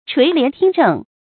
注音：ㄔㄨㄟˊ ㄌㄧㄢˊ ㄊㄧㄥ ㄓㄥˋ
垂簾聽政的讀法